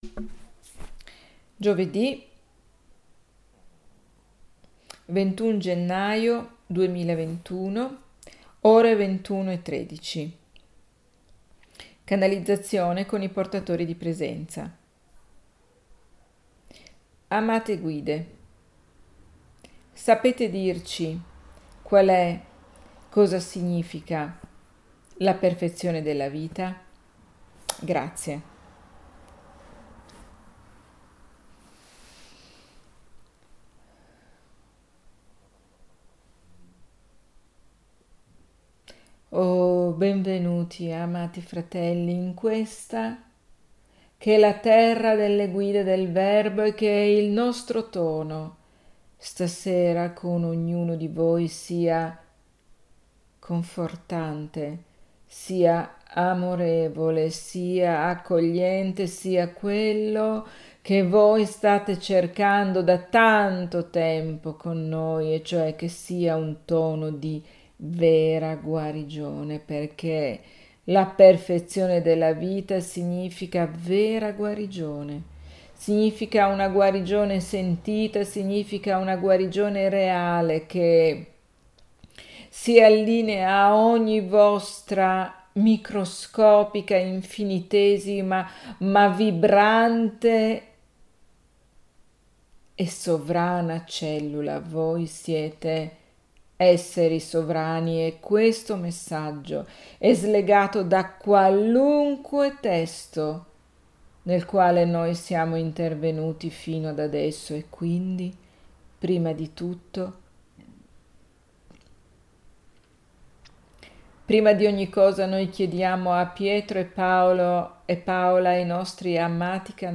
Gioved� 21 gennaio 2021 La Perfezione della Vita [l'audio della sessione di channeling] [il video integrale dell'evento] Ore ~ 21,13 �Amate Guide sapete dirci qual �, cosa significa la perfezione della vita?